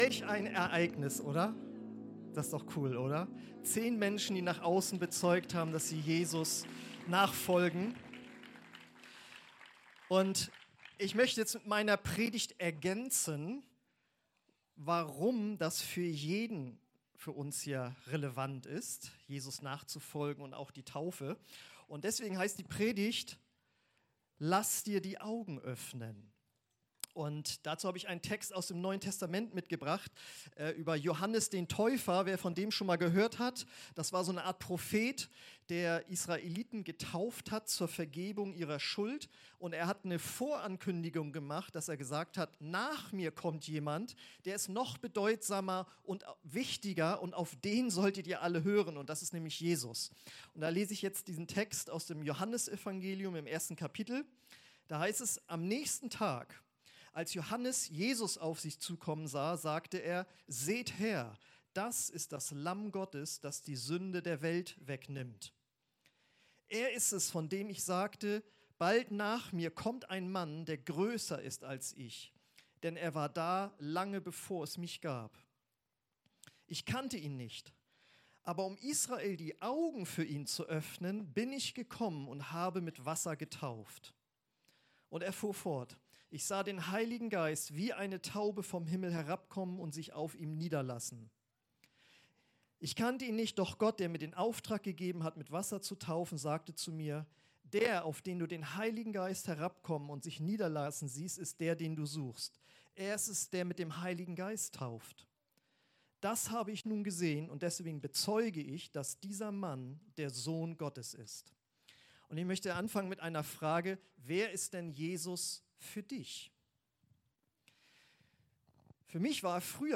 Predigt
Juni 2025 Taufgottesdienst mit Sommerfest Anhören Themen